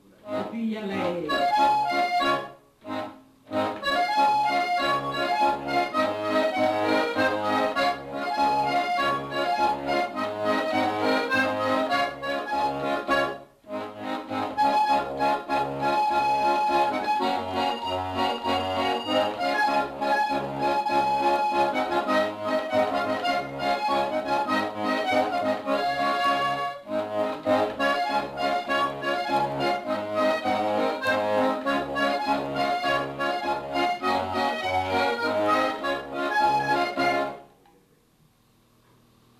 Quadrille (3e figure)